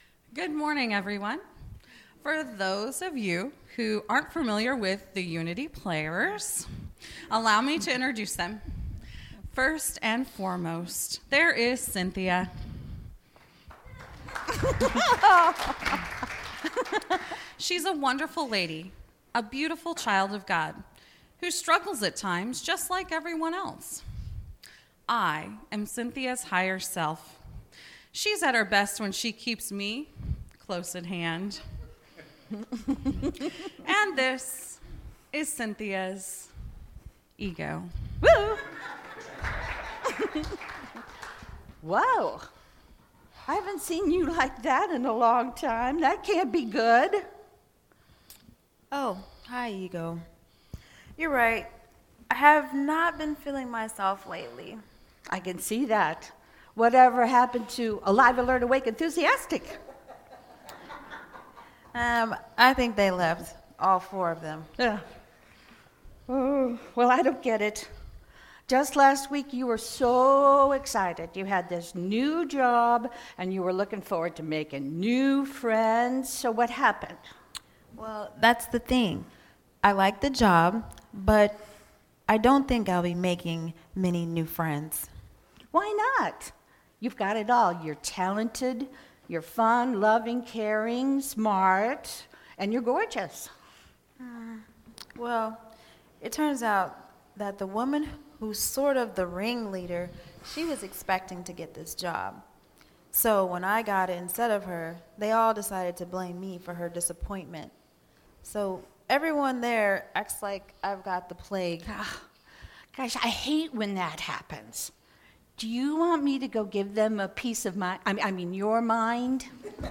Series: Sermons 2019